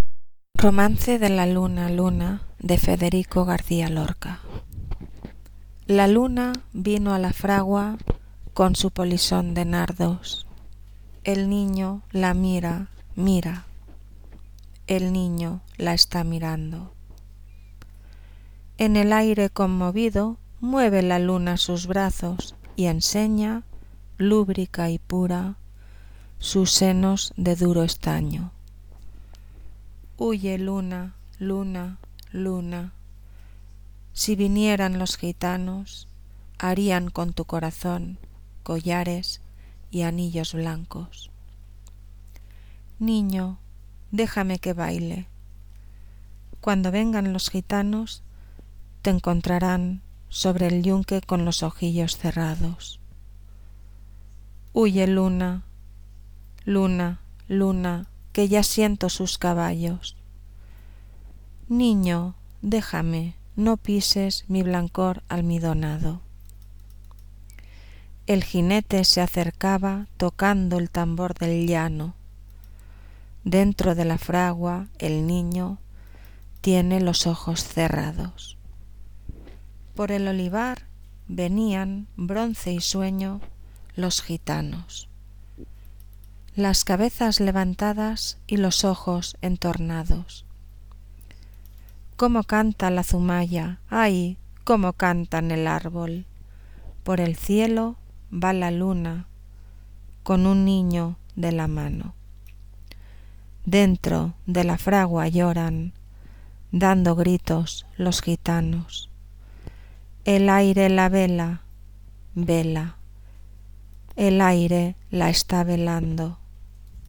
poema
lectura
narración
Sonidos: Voz humana